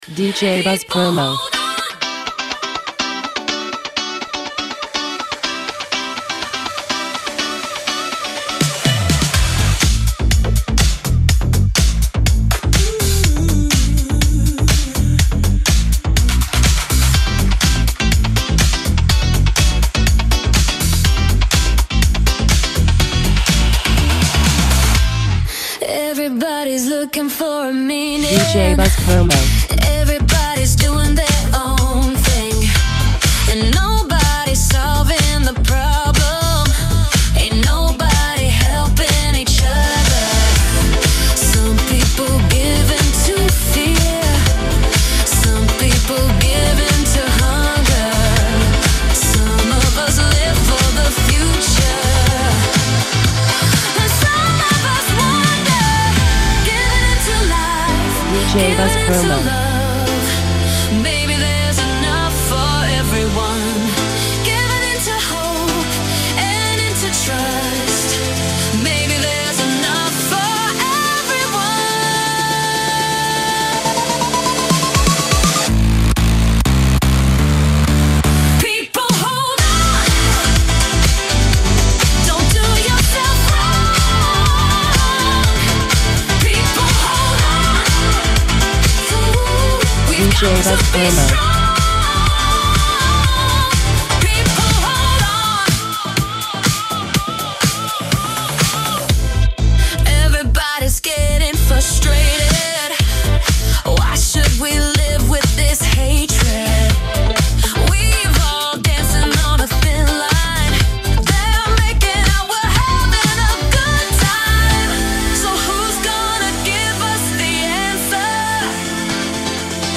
a powerful, floor-ready remake
Original Mix